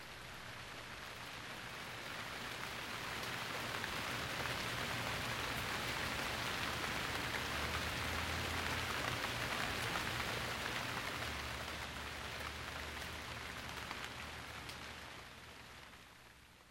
LLOVIENDO
Tonos EFECTO DE SONIDO DE AMBIENTE de LLOVIENDO
Lloviendo.mp3